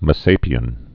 (mə-sāpē-ən)